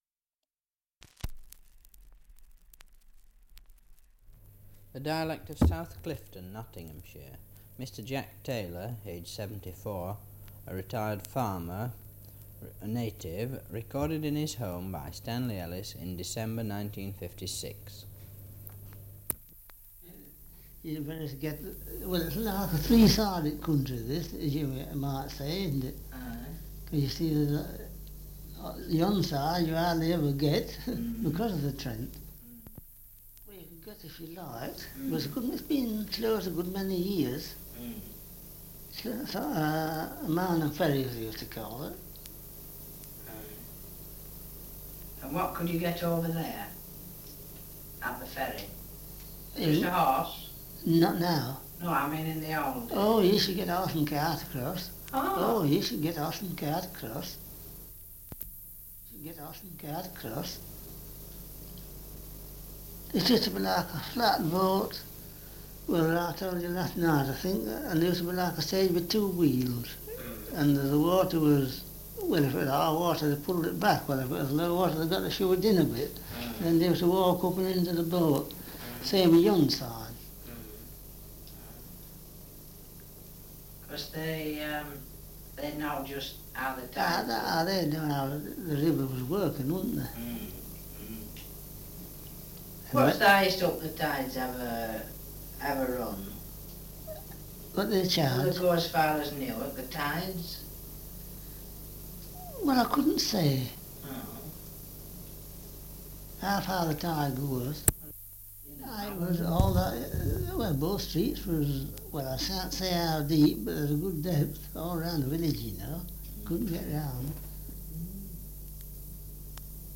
1 - Survey of English Dialects recording in South Clifton, Nottinghamshire
78 r.p.m., cellulose nitrate on aluminium